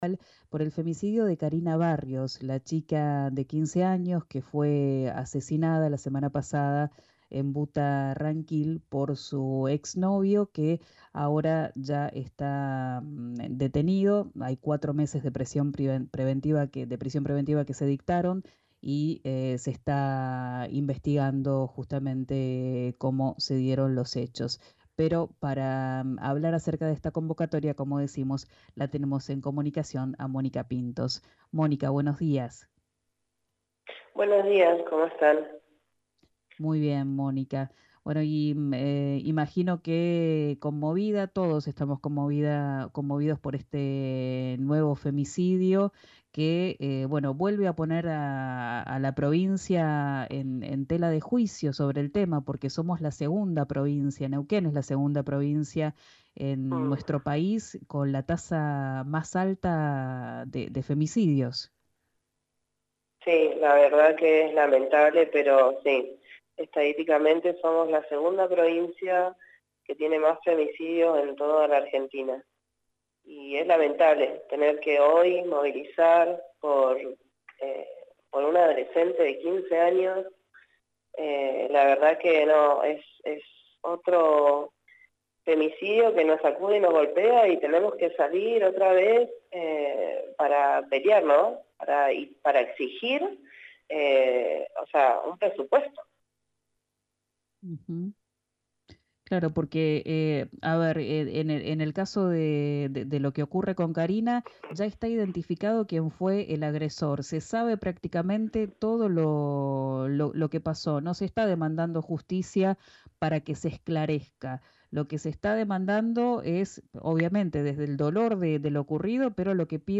habló con RÍO NEGRO RADIO.